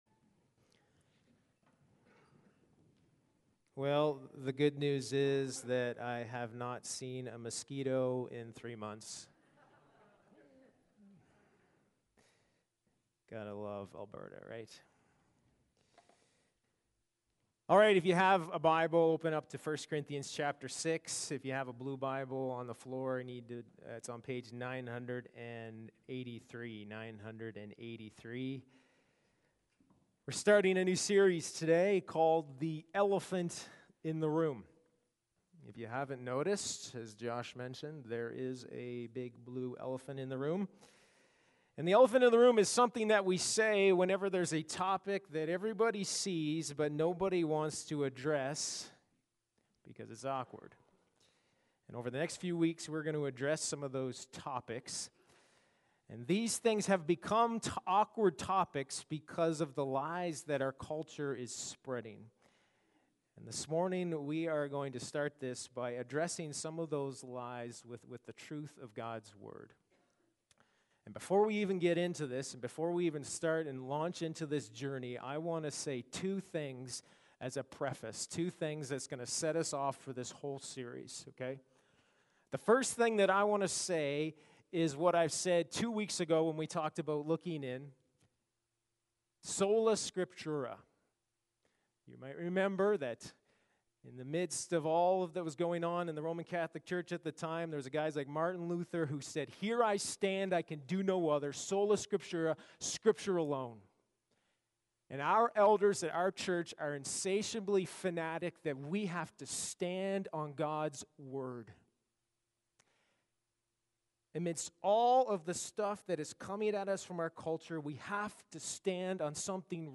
Sermons | Sturgeon Alliance Church